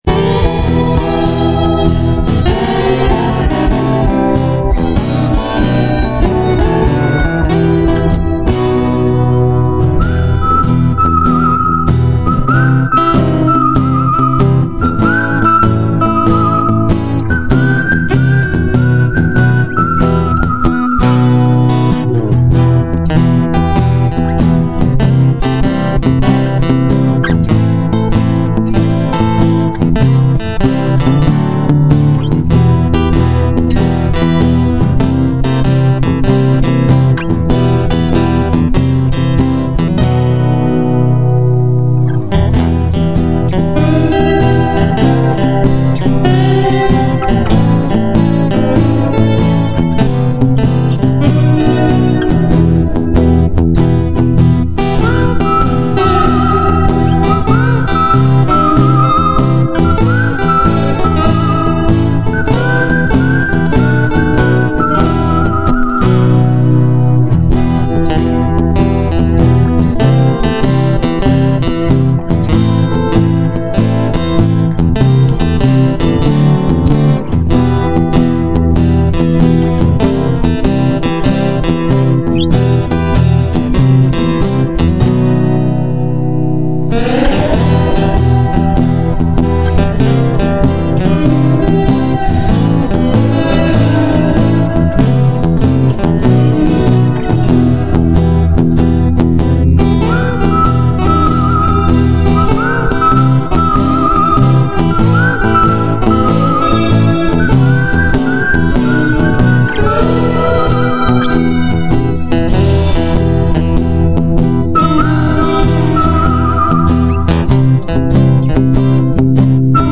hoefliBluesInst&Whistle.MP3